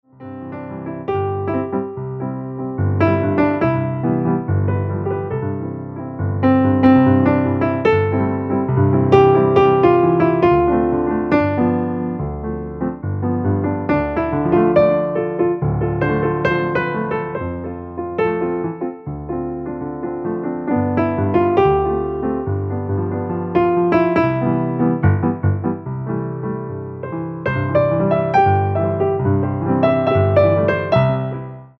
entertainment pianists